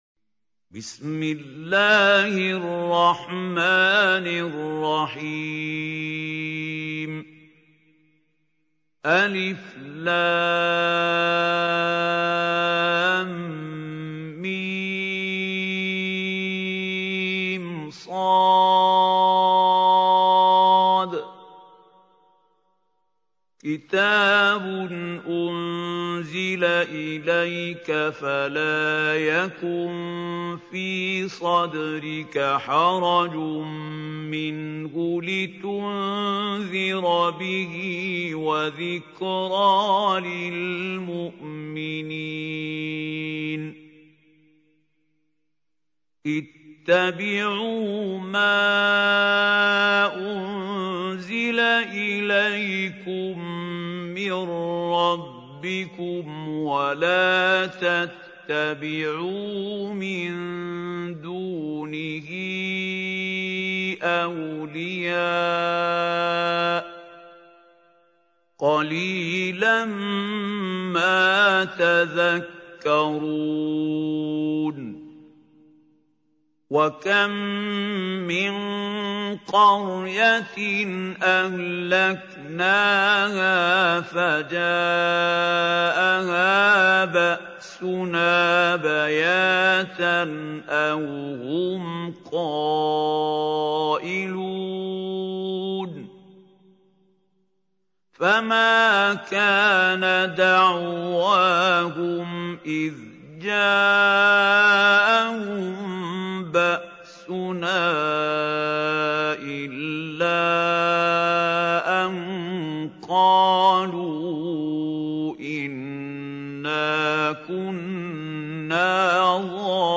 Surah Araf Recitation by Mahmoud Al Hussary
This Surah is 7th Chapter of Quran e Kareem. Listen this surah in the voice of Mahmoud Khalil Al Hussary.